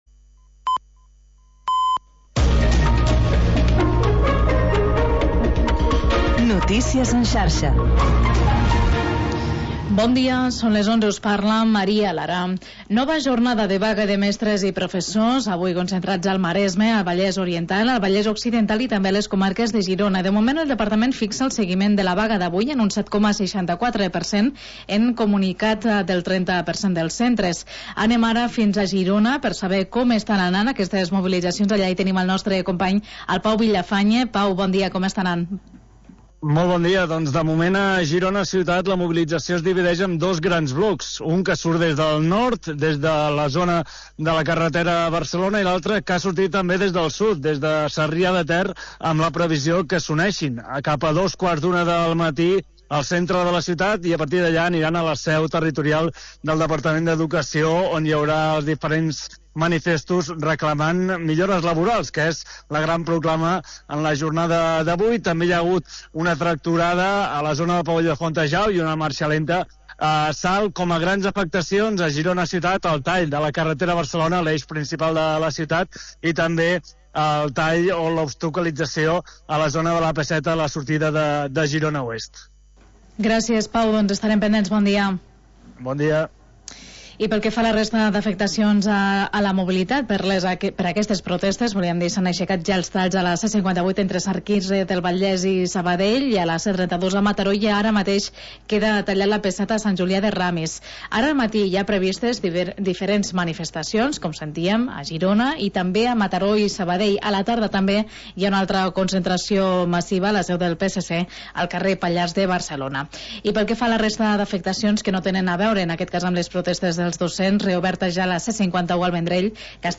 Magazín d'entreteniment per encarar el dia